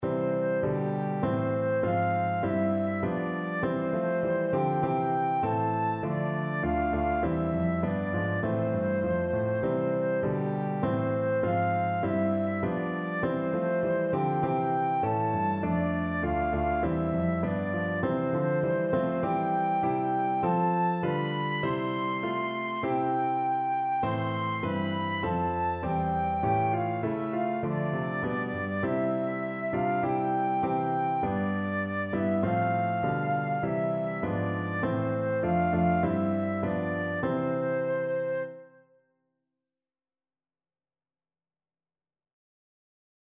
Flute version
Flute
C major (Sounding Pitch) (View more C major Music for Flute )
Moderato
4/4 (View more 4/4 Music)
G5-C7
Traditional (View more Traditional Flute Music)
Israeli